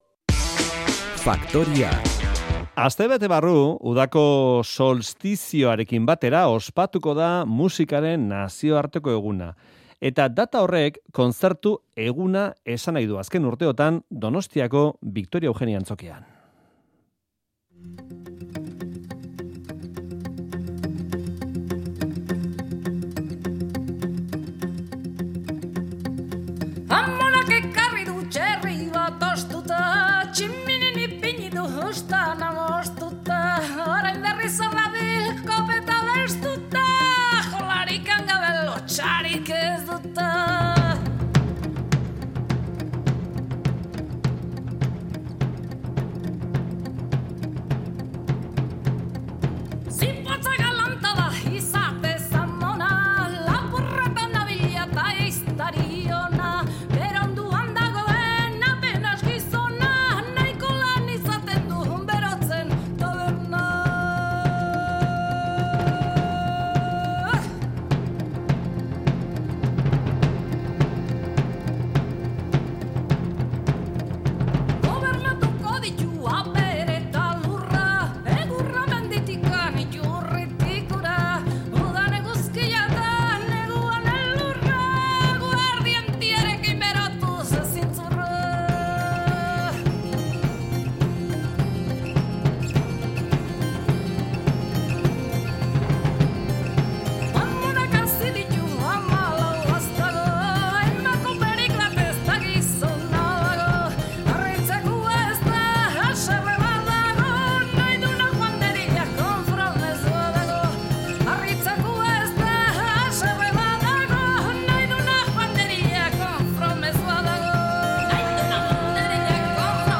Errioxako bere etxean izan gara.